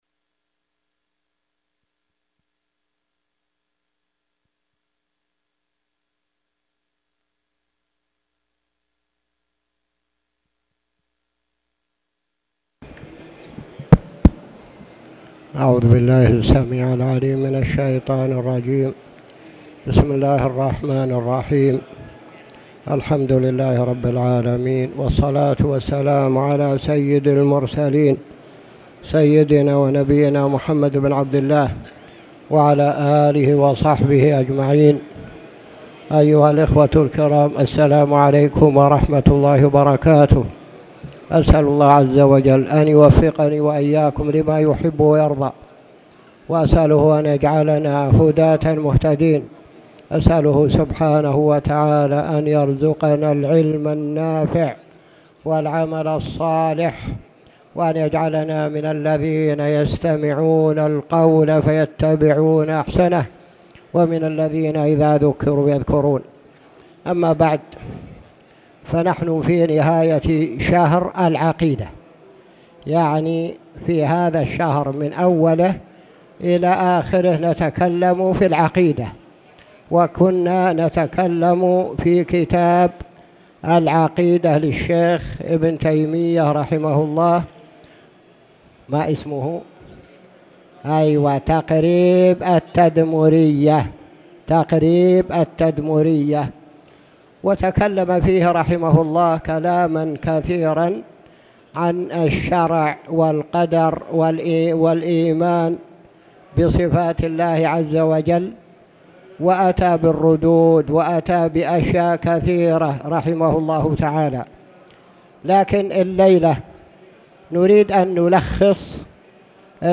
تاريخ النشر ٢٦ صفر ١٤٣٨ هـ المكان: المسجد الحرام الشيخ